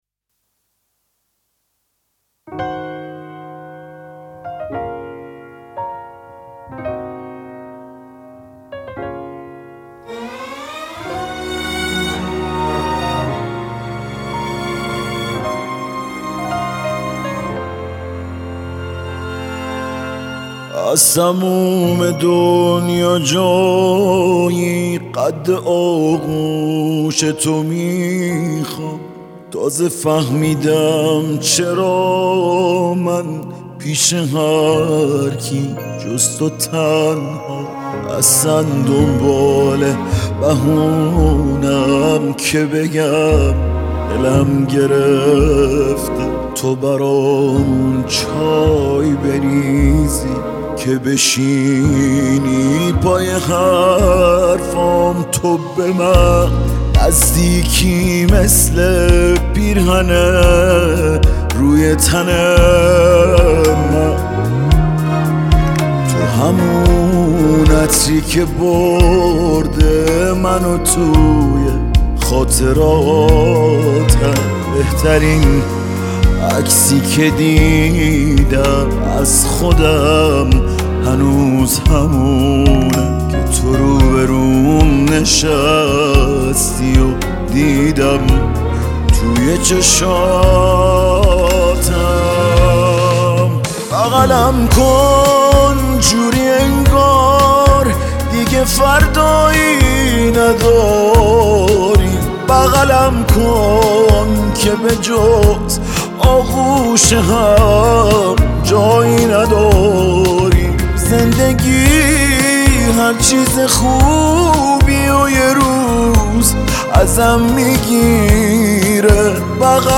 تک آهنگ عاشقانه